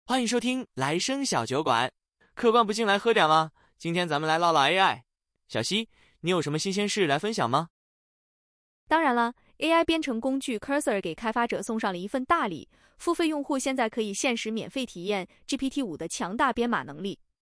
edgeTTS.wav